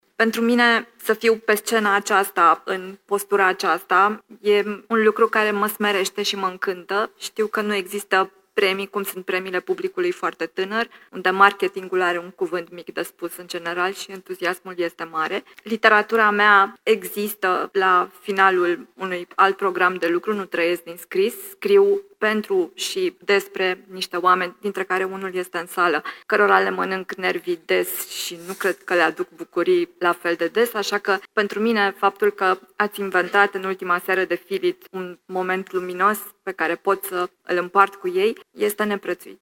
Într-un discurs susținut pe scena Teatrului Național din Iași